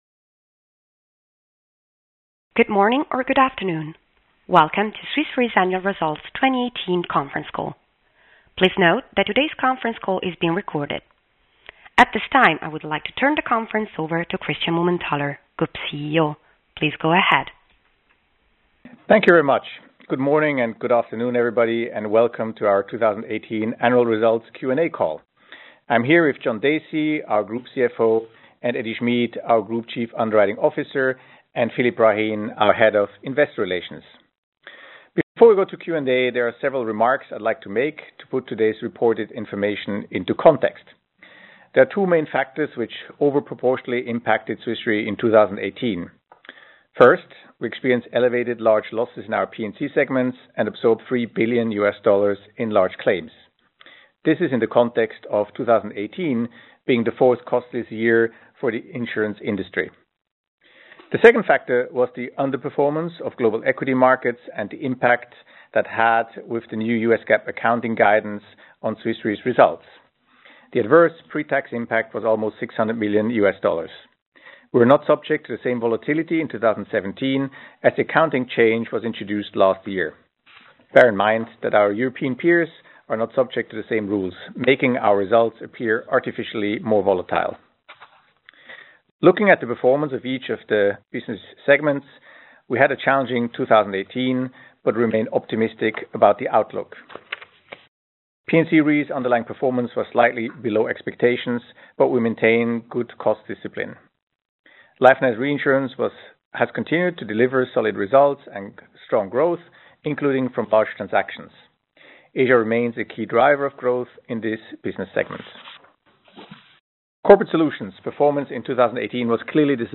fy-2018-call-recording.mp3